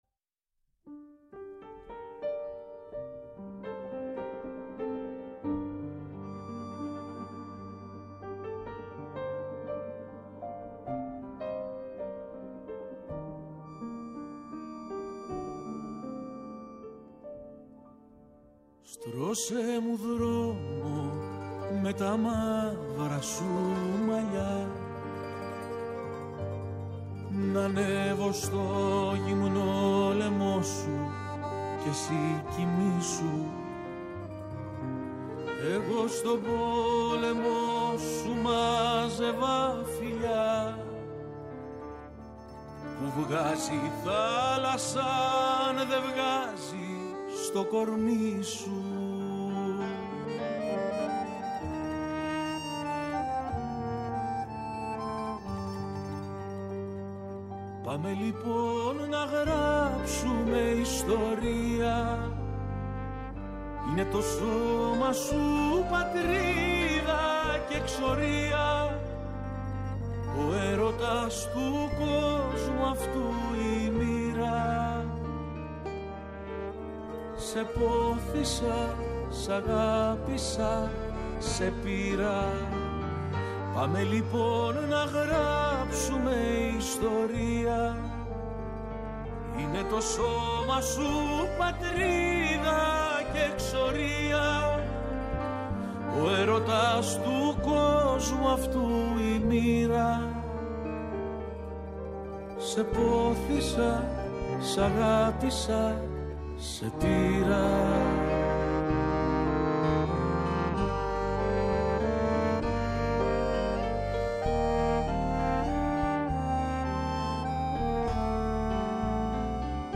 Καλεσμένος σήμερα στην εκπομπή ο συνθέτης Μινως Μάτσας..